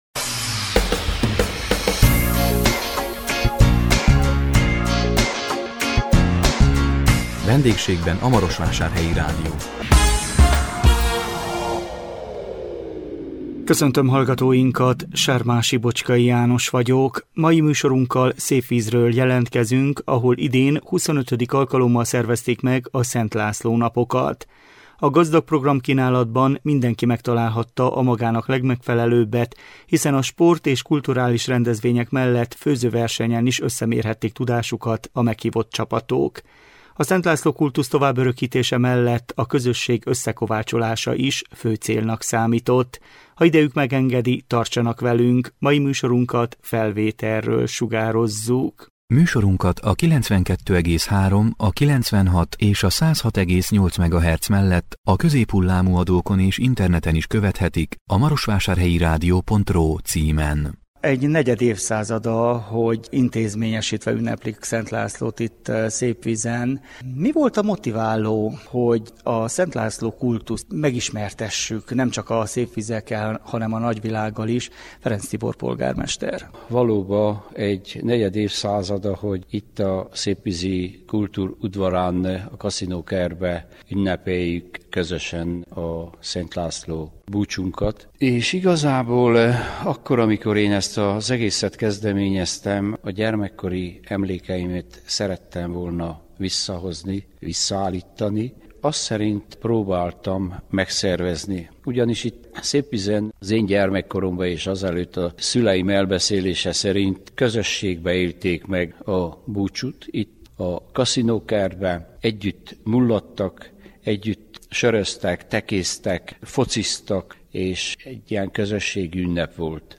A 2024 július 4-én közvetített VENDÉGSÉGBEN A MAROSVÁSÁRHELYI RÁDIÓ című műsorunkkal Szépvízről jelentkeztünk, ahol idén XXV. alkalommal szervezték meg a Szent László napokat. A gazdag programkínálatban mindenki megtalálhatta a magának legmegfelelőbbet, hiszen a sport- és kulturális rendezvények mellett főzőversenyen is összemérhették tudásukat a meghívott csapatok. A Szent László kultusz továbbörőkítése mellett a közösség összekovácsolása is fő célnak számított.